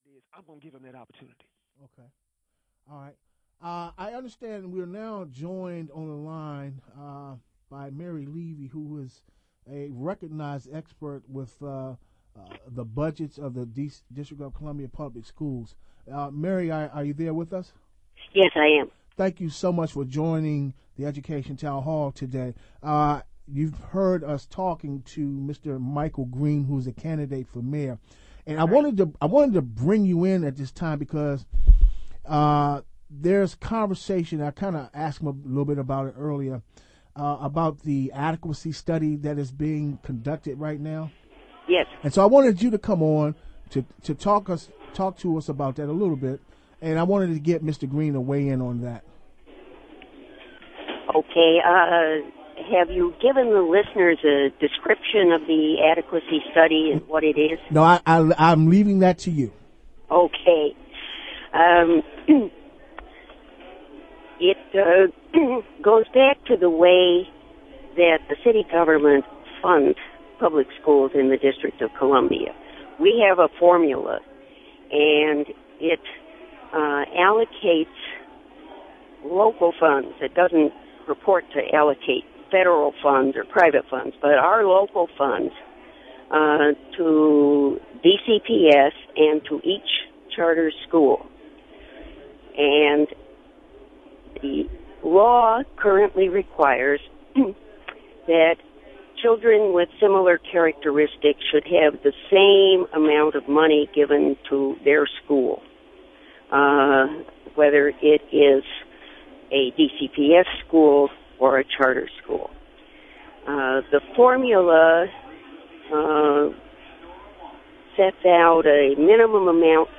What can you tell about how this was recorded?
PLEASE NOTE: The discussion includes comments from two mayoral candidates. The Education Town Hall apologizes for background noise, due to the location of one candidate’s call; the Education Town Hall strives to include as many voices as possible.